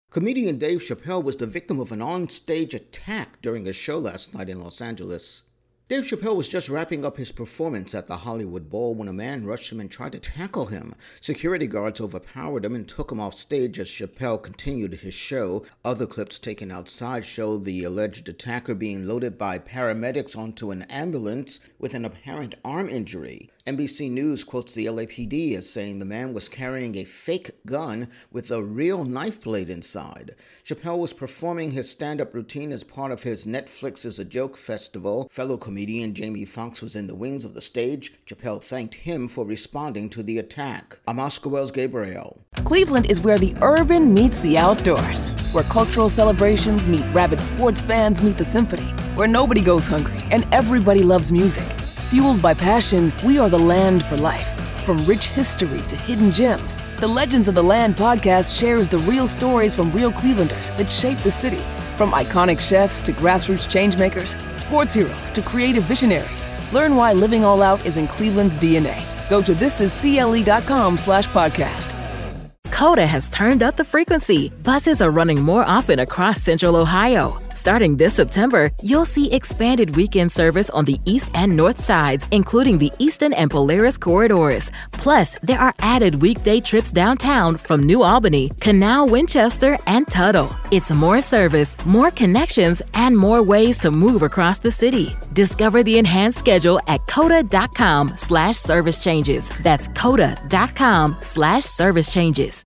Dave Chappelle Attacked Intro and Voicer